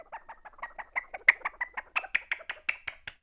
Index of /90_sSampleCDs/NorthStar - Global Instruments VOL-2/PRC_JungleSounds/PRC_JungleSounds
PRC CHIMP09R.wav